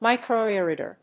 アクセント・音節mícro・rèader